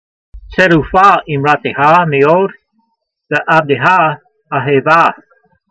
Sound (Psalm 119:140) Transliteration: tser(d)oo fa eemratey ha me' od ve ' avde ha a' hay' v ah Vocabulary Guide: Your word is pure exceedingly and your servant loves it. Translation: Your word is pure exceedingly and your servant loves it.